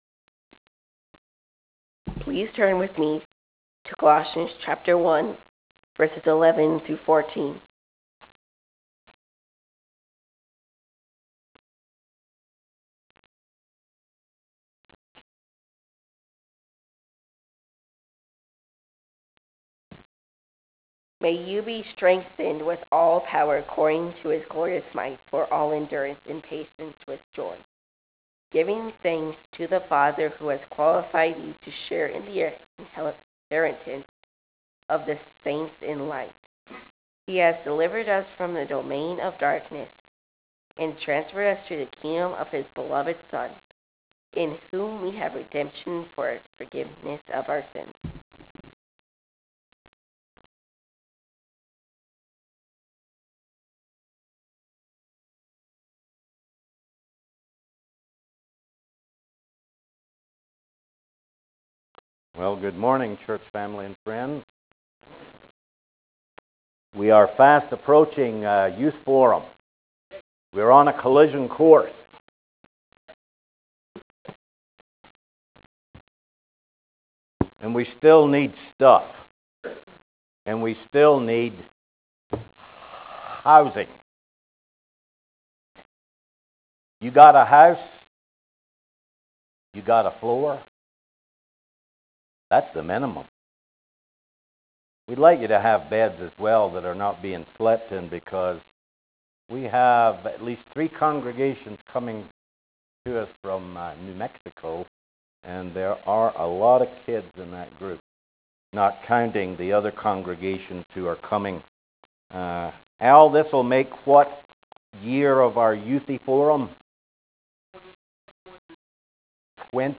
April 6 2014 AM sermon
April-6-2014-AM-sermon.wav